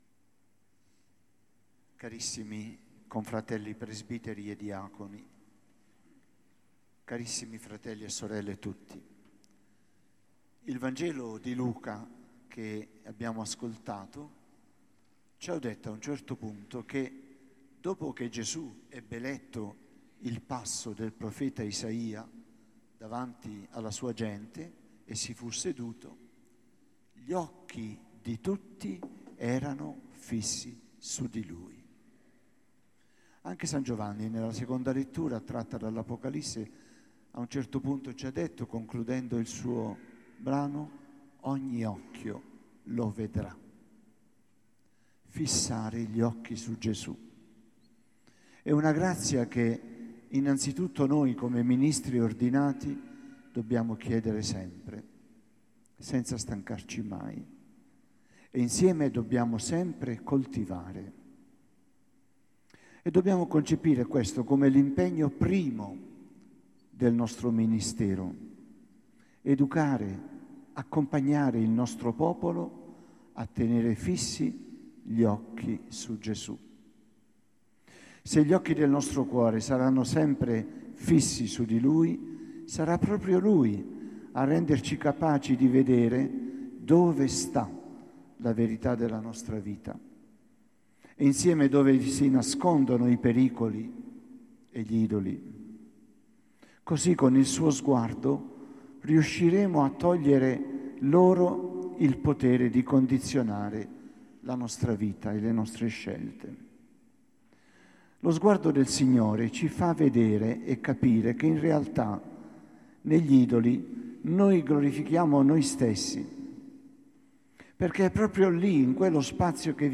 Mercoledì Santo 5 aprile, il vescovo Mons. Luigi Mansi ha presieduto la S. Messa Crismale nella Chiesa Cattedrale, hanno concelebrato i sacerdoti provenienti da tutta la Diocesi. La Messa crismale prende in nome dalla preghiera di consacrazione del sacro Crisma, olio misto a profumo.
Crismale-2023-omelia.mp3